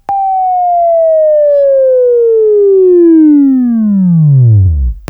Sweeping training / Wavefile / Buzz